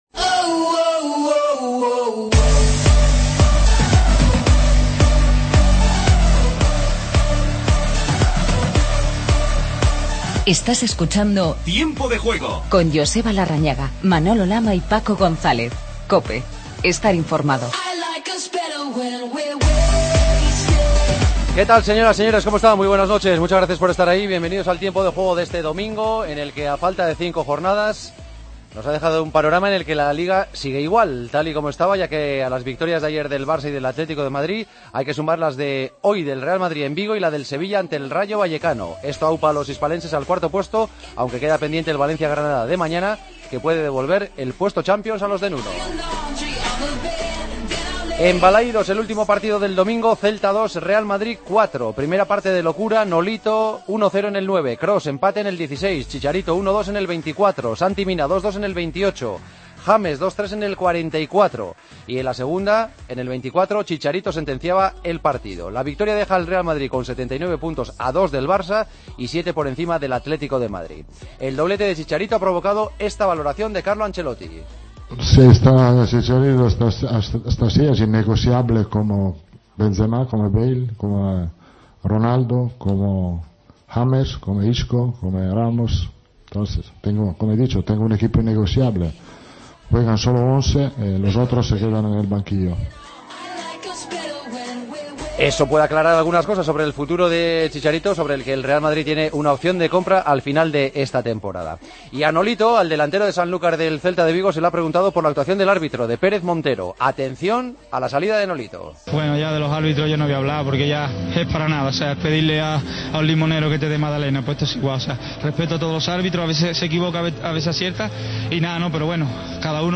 El Real Madrid se mantiene a dos puntos del Barcelona tras ganar al Celta. Entrevistas a Nolito y Santi Mina; y escuchamos a Chicharito y Marcelo. Conocemos la actualidad del Barcelona.